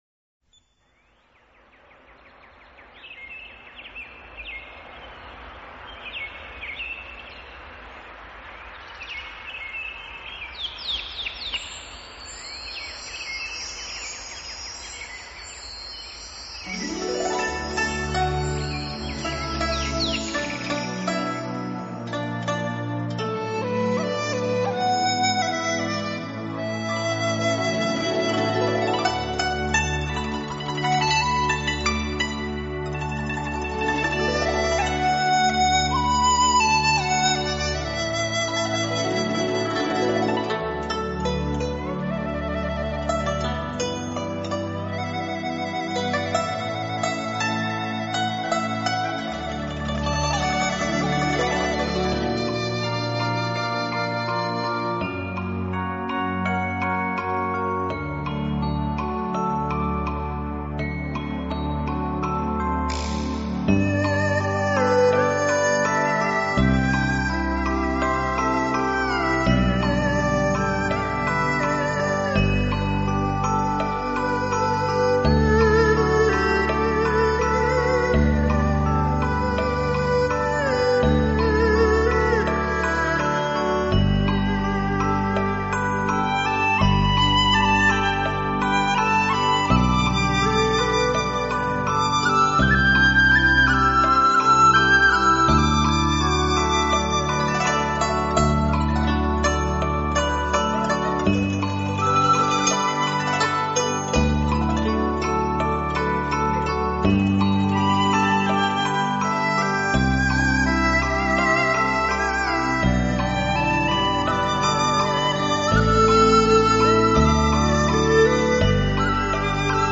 道家仙乐